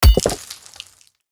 axe-mining-ore-6.ogg